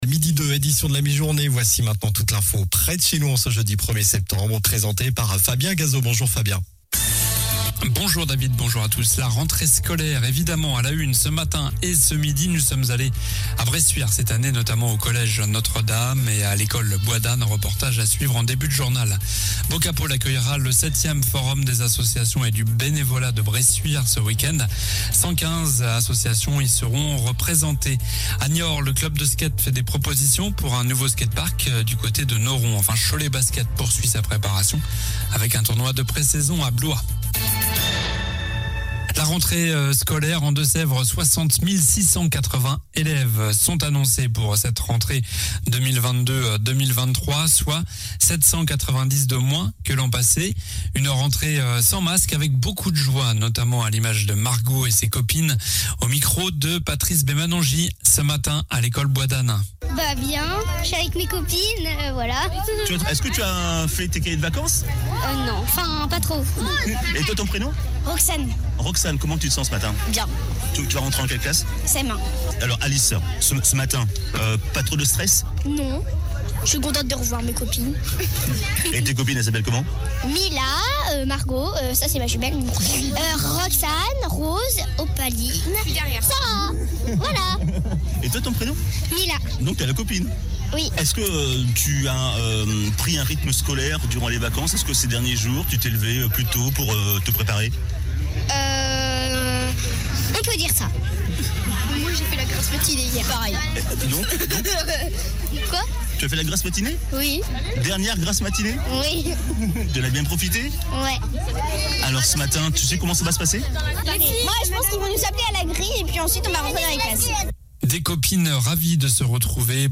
Journal du jeudi 1er septembre (midi)
- La rentrée scolaire évidemment à la une ce matin et ce midi. Reportages.